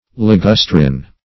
Search Result for " ligustrin" : The Collaborative International Dictionary of English v.0.48: Ligustrin \Li*gus"trin\ (l[-i]*g[u^]s"tr[i^]n), n. (Chem.)